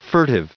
added pronounciation and merriam webster audio
1770_furtive.ogg